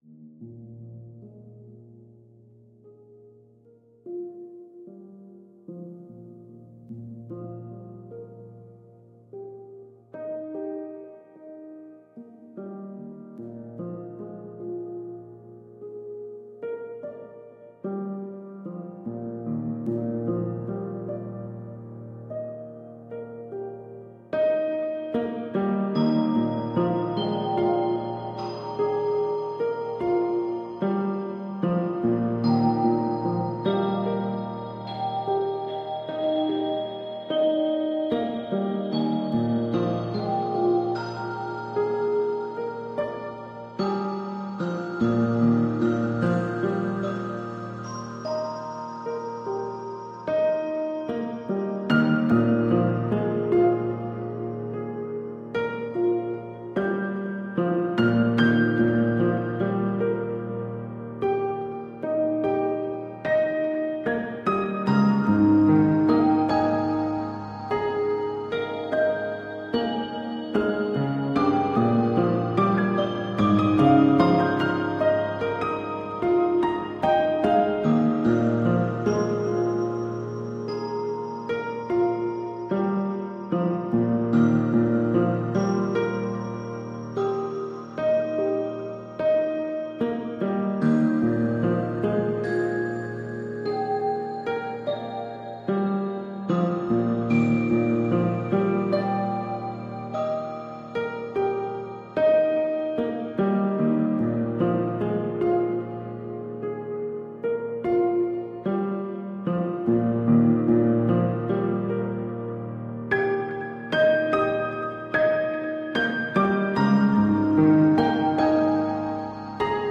All volums remastered to same perceived volumes.